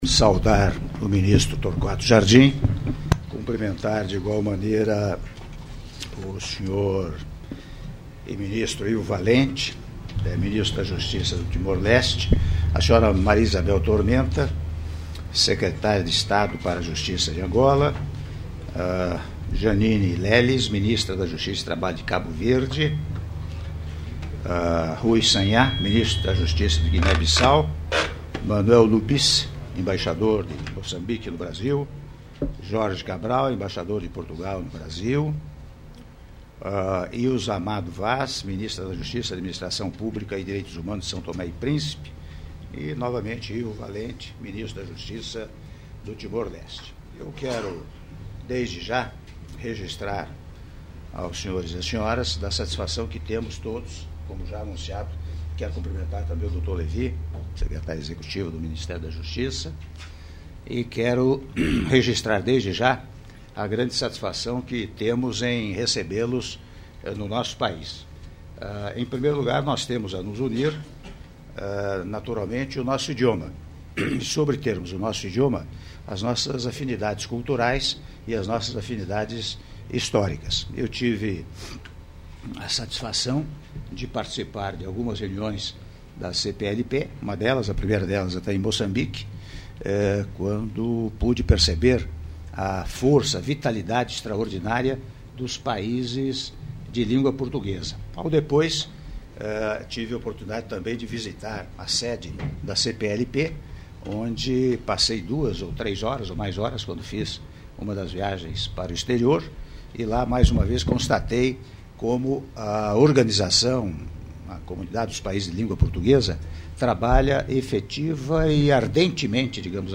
Áudio do discurso do Presidente da República, Michel Temer, durante abertura da 15ª Conferência dos Ministros da Justiça da Comunidade dos Países de Língua Portuguesa - CPLP- Brasília/DF- (06min36s)